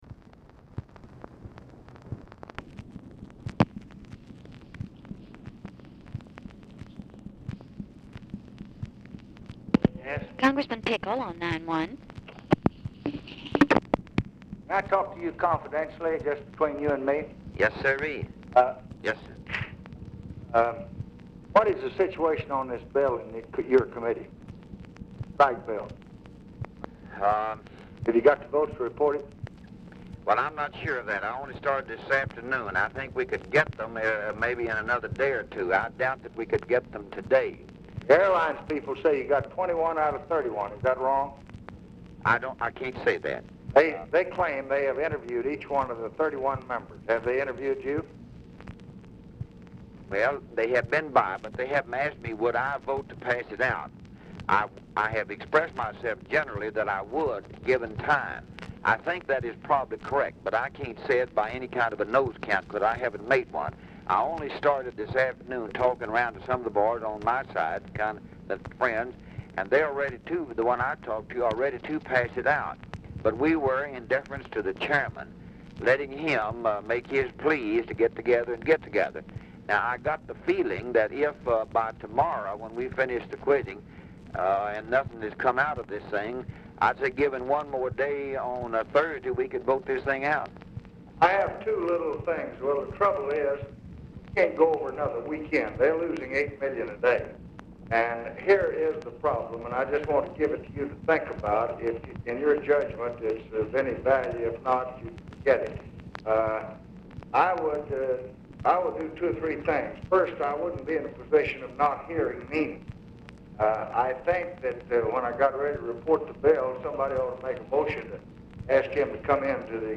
Telephone conversation
LBJ APPARENTLY ON SPEAKERPHONE
Format Dictation belt